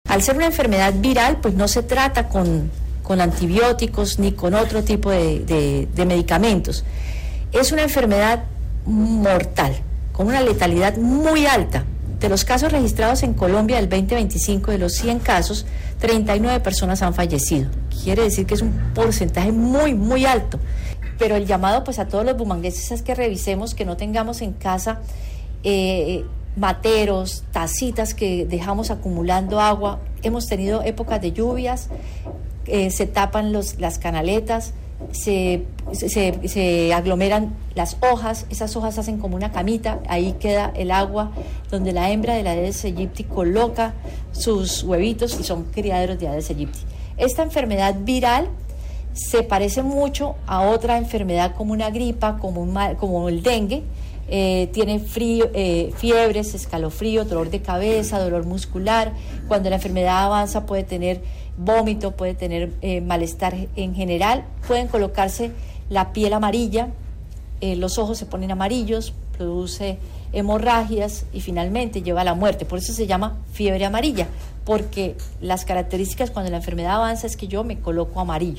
Claudia Amaya, secretaria de Salud de Bucaramanga